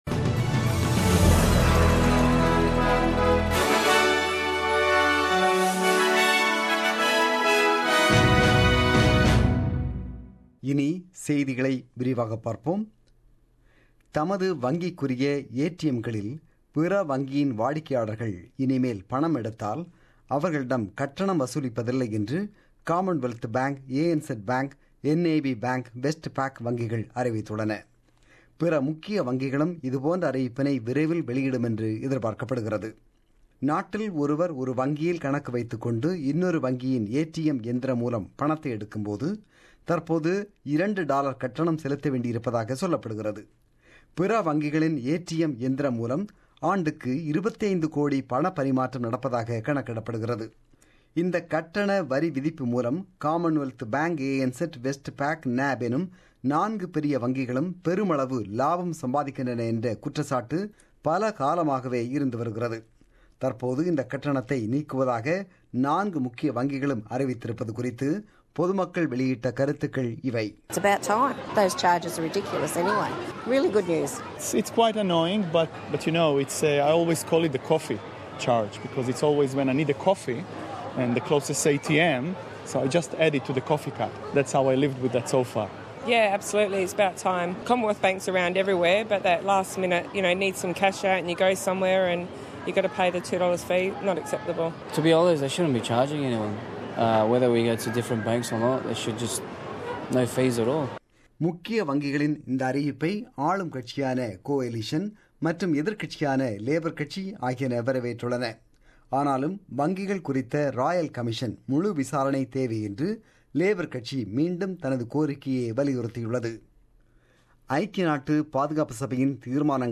The news bulletin broadcasted on 24 September 2017 at 8pm.